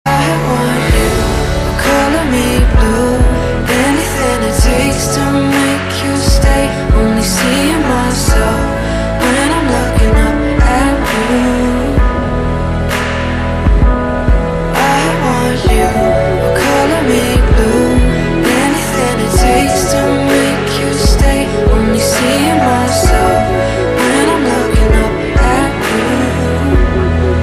M4R铃声, MP3铃声, 欧美歌曲 86 首发日期：2018-05-15 01:06 星期二